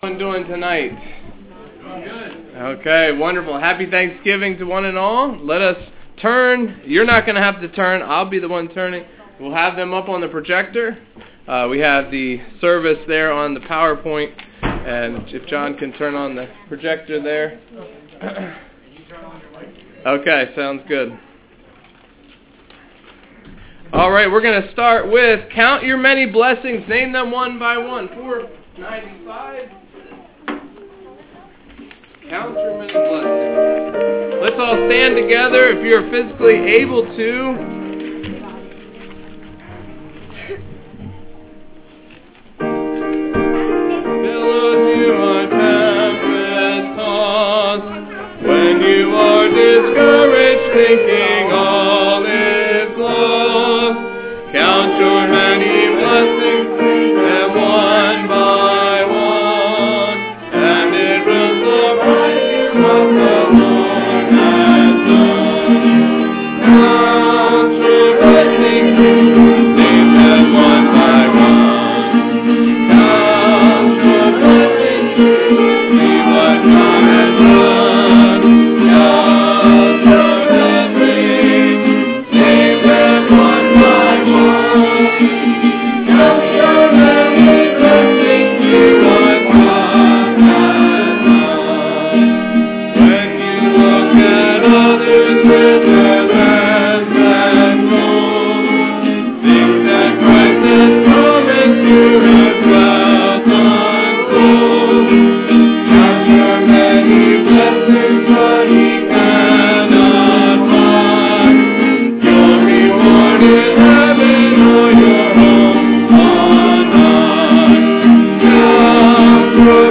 THANKSGIVING EVE SERVICE